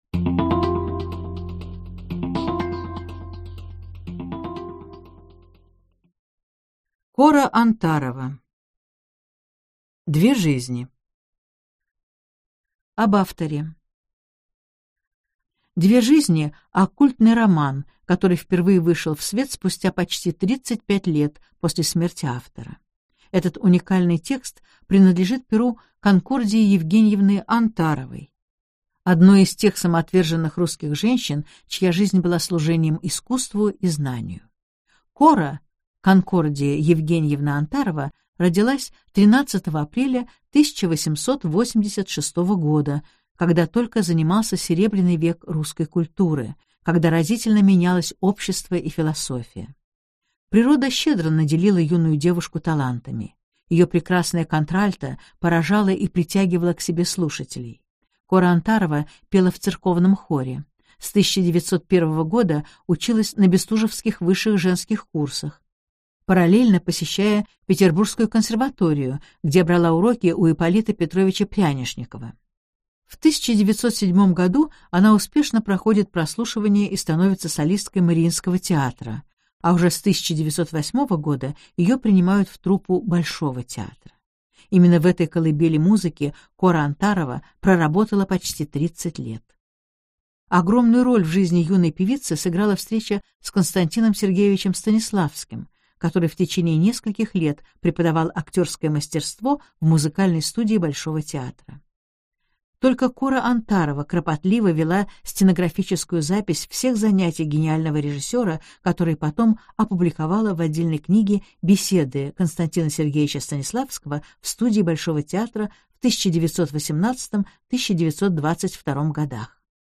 Аудиокнига Две жизни: I часть, в обновленной редакции | Библиотека аудиокниг